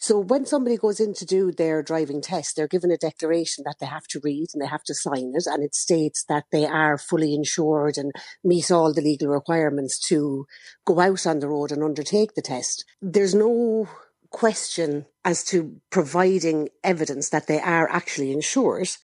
Driving Instructor